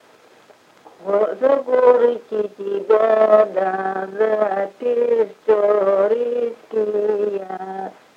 Вставка слога